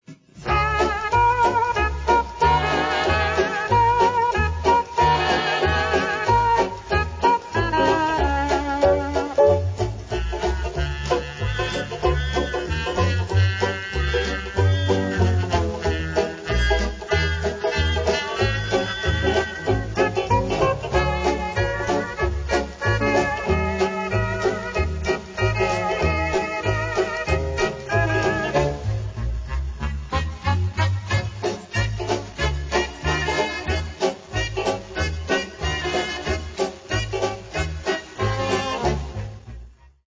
Tanzmusik